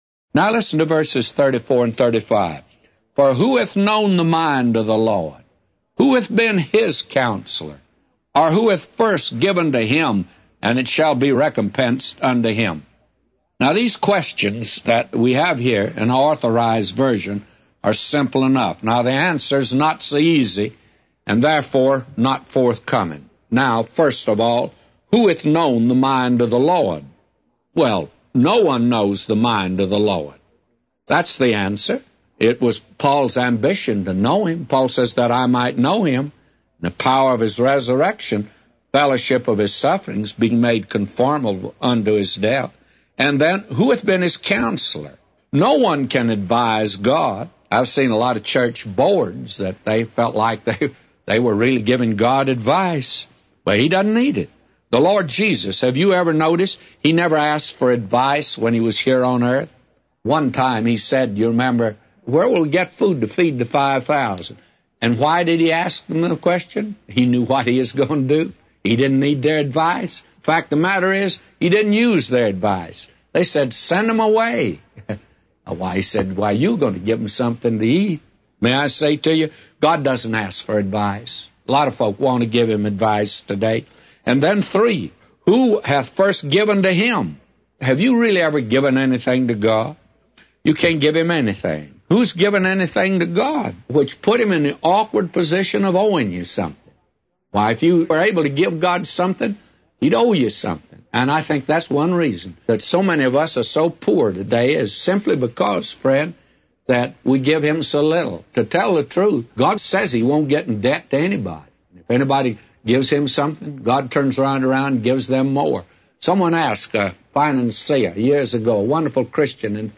A Commentary By J Vernon MCgee For Romans 11:34-36